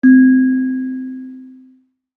kalimba1_circleskin-C3-pp.wav